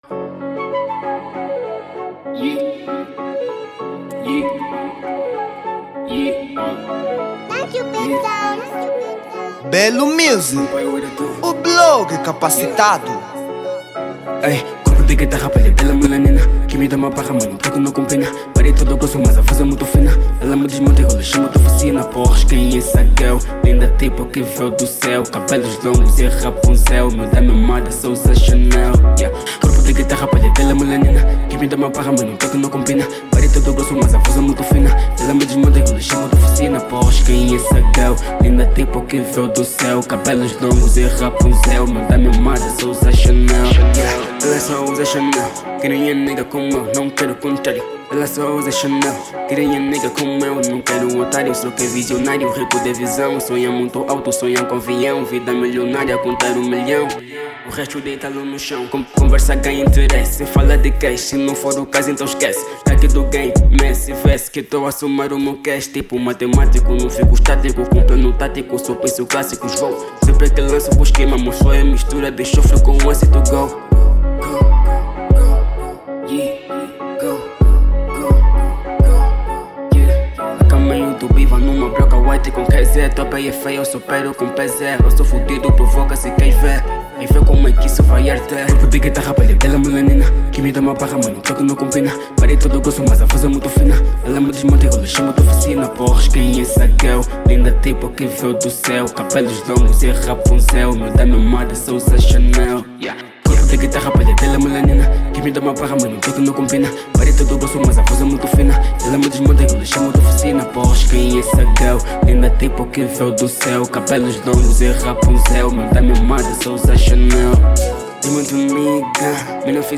Trap Formato